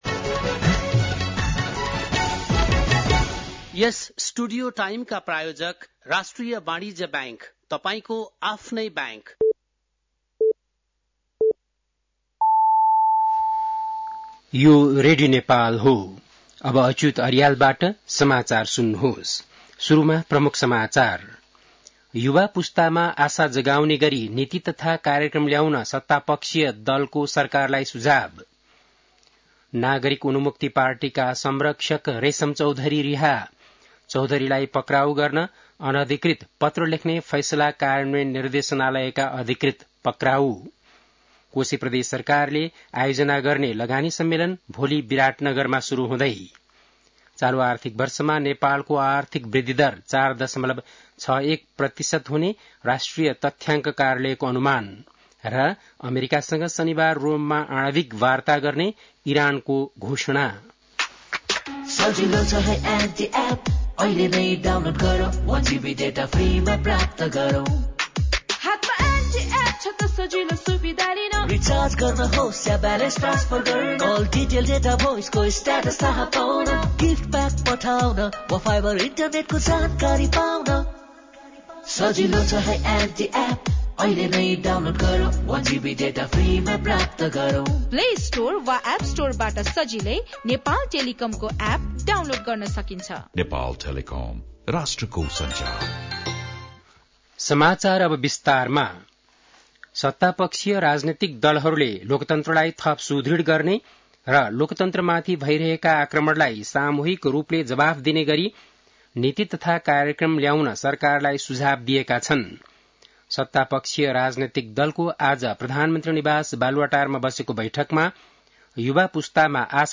बेलुकी ७ बजेको नेपाली समाचार : १७ वैशाख , २०८२